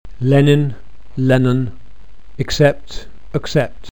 In RP and many other accents, weak and weak are in contrastive (overlapping) distribution - they can differentiate between minimal pairs such as: